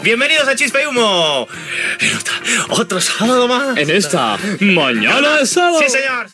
Espai emès dins de la programació de "Los 40 Principales". Salutació inicial
Entreteniment